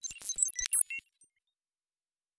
pgs/Assets/Audio/Sci-Fi Sounds/Electric/Data Calculating 3_2.wav at master
Data Calculating 3_2.wav